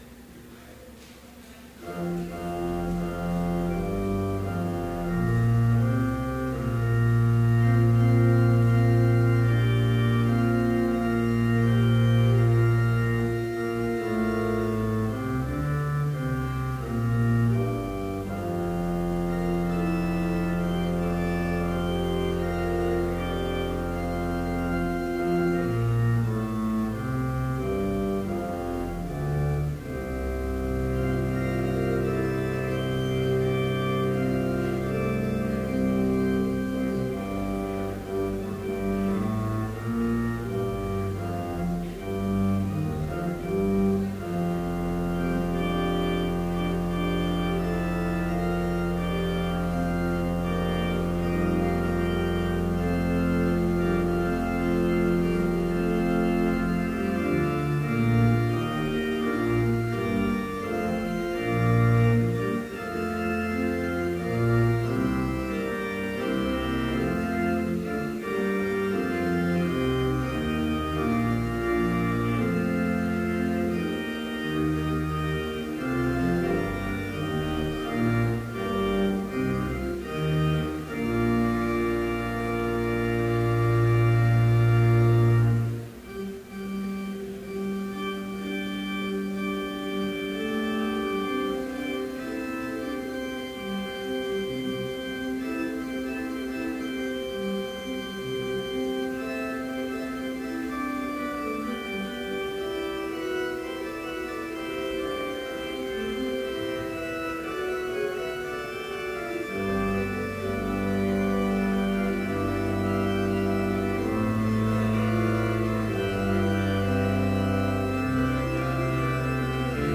Complete service audio for Chapel - December 1, 2014